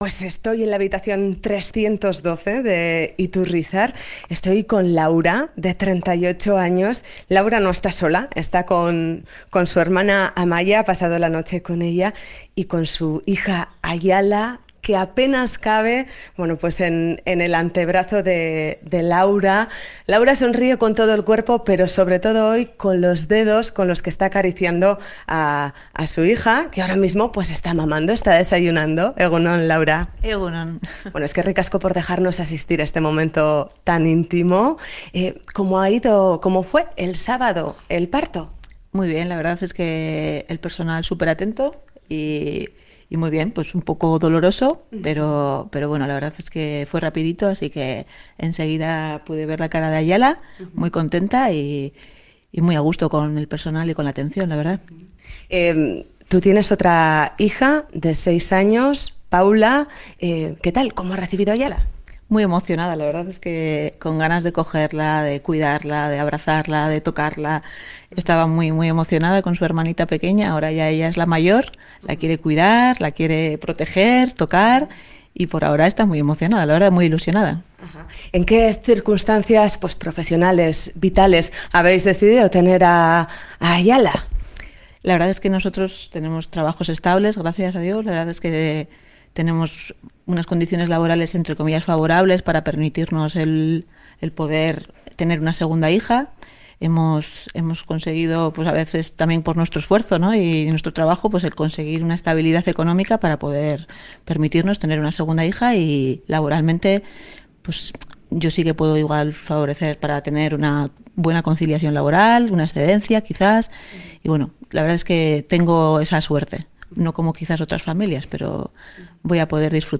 Radio Euskadi BOULEVARD Especial natalidad en Euskadi Última actualización: 05/03/2018 11:33 (UTC+1) 'Boulevard' se ha desplazado hasta la maternidad de Basurto para este especial natalidad en Euskadi.